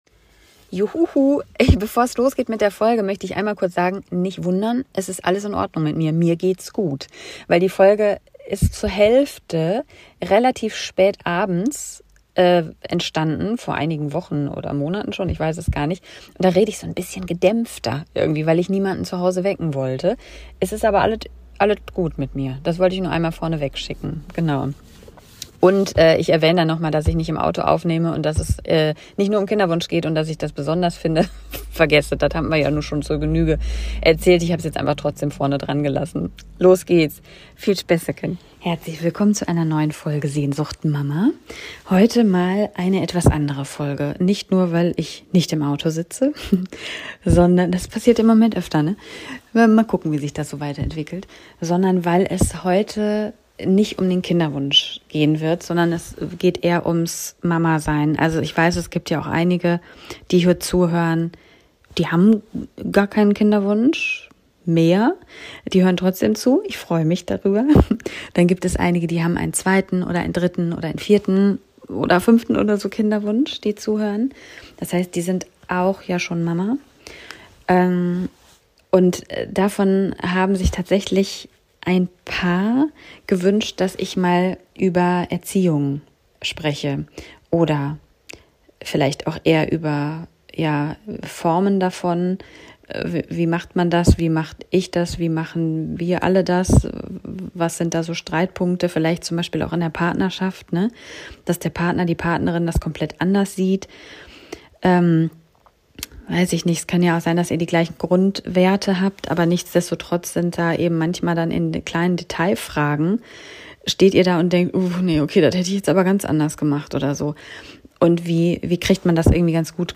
Eher leise, auch mal fragend, vielleicht sogar zweifelnd und verletzlich.